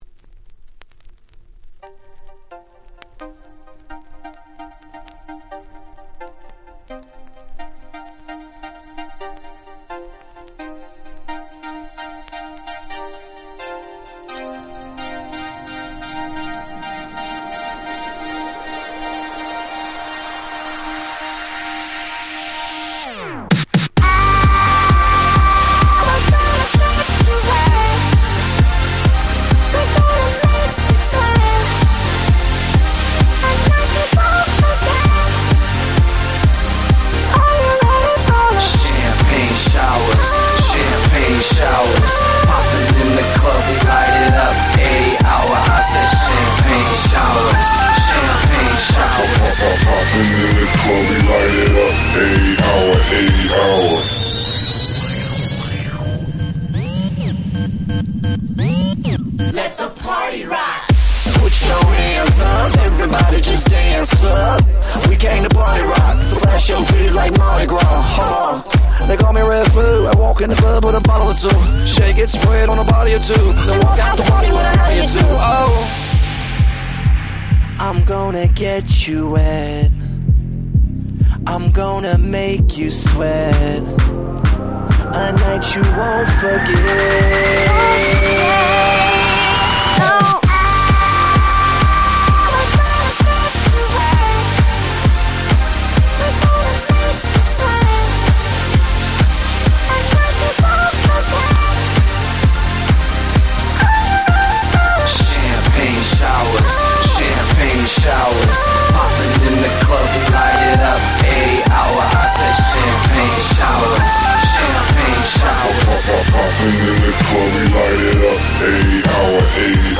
11' Super Hit EDM !!